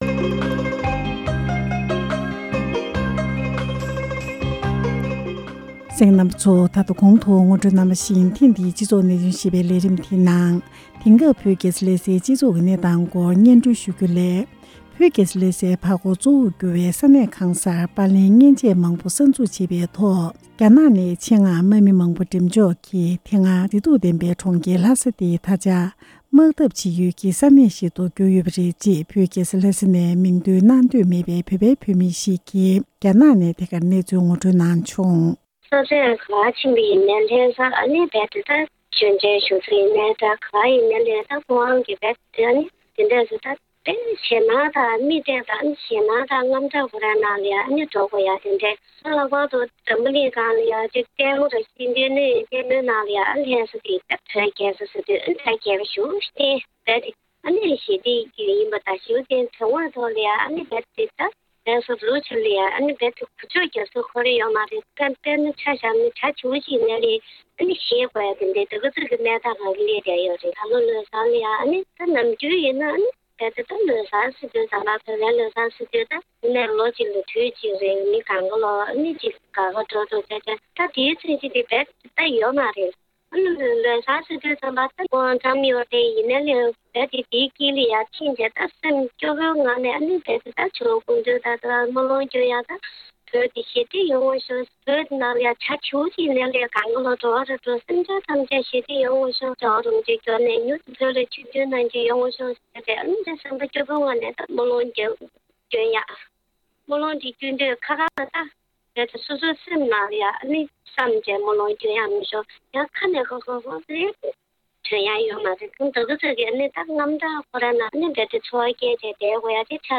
དེང་སྐབས་བོད་ཀྱི་རྒྱལ་ས་ལྷ་སའི་སྤྱི་ཚོགས་ཀྱི་གནས་སྟངས་སྐོར་བོད་ནས་ཞལ་པར་བརྒྱུད་ང་ཚོ་རླུང་འཕྲིན་ཁང་ལ་འགྲེལ་བརྗོད་གནང་བའི་དམིགས་བསལ་ལས་རིམ།